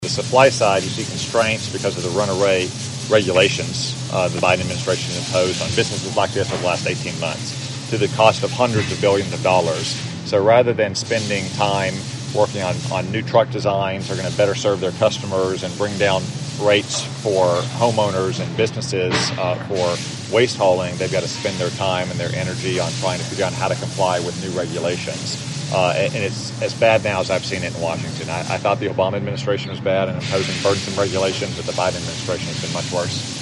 Feenstra and Cotton made their comments at Scranton Manufacturing following their tour.